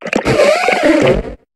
Cri de Coudlangue dans Pokémon HOME.